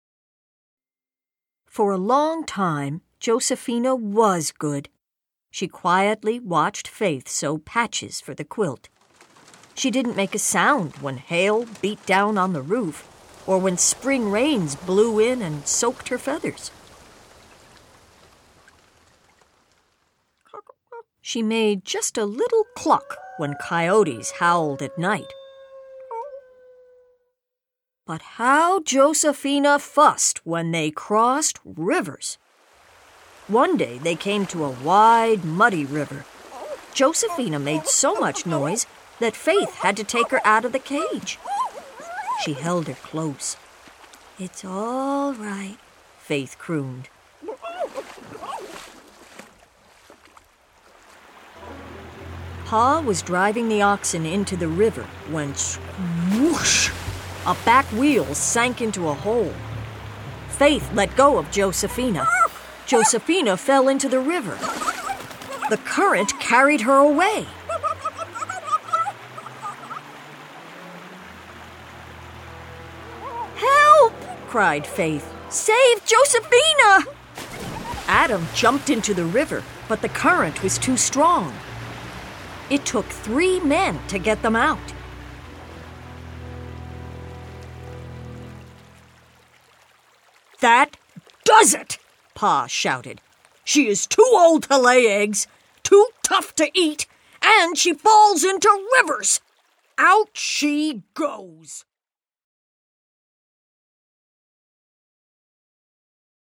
When the famed Regent Diamond is stolen, Julieta is in the middle of a high stakes mystery. With a bilingual family and international travel, the full cast portrays a range of accents, as well as phrases in Spanish and French, with panache.